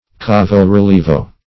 Search Result for " cavo-rilievo" : The Collaborative International Dictionary of English v.0.48: Cavo-rilievo \Ca"vo-ri*lie"vo\, n. [It.]
cavo-rilievo.mp3